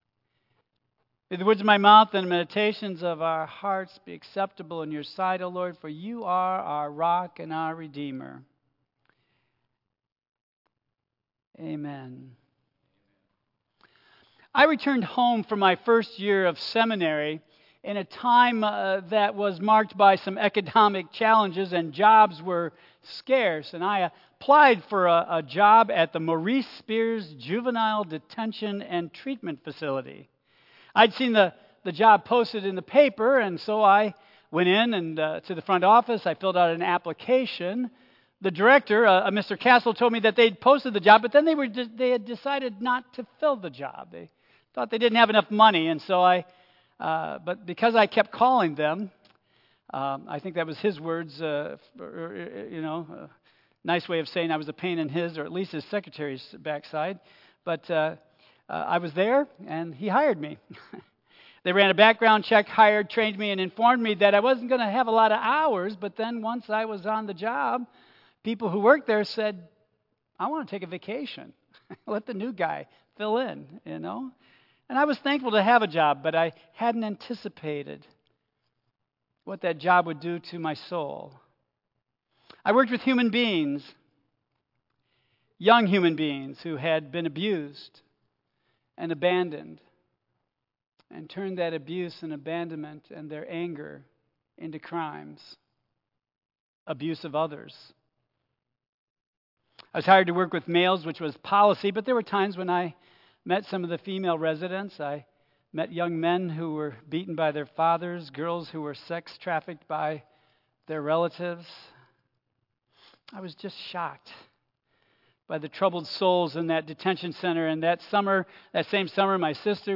Tagged with Central United Methodist Church , Michigan , Sermon , Waterford , Worship Audio (MP3) 8 MB Previous Listening for God Next Praise God!